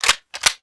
gunpickup2.wav